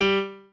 piano7_3.ogg